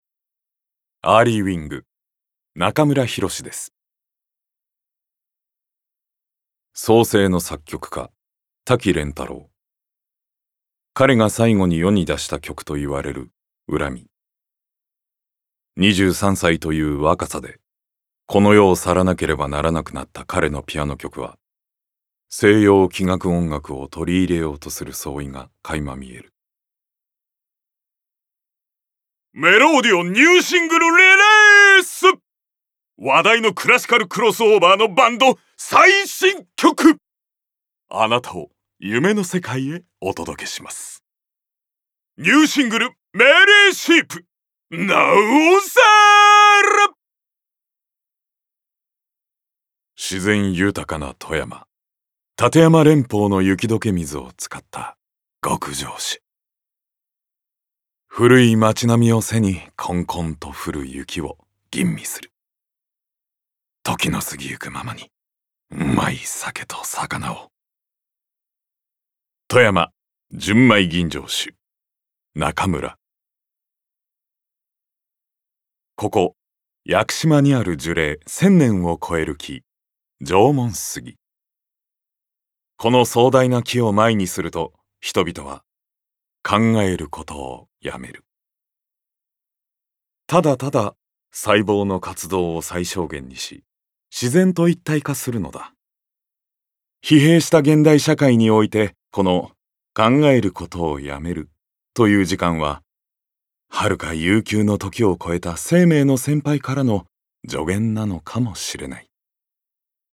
ボイスサンプル
ナレーションALL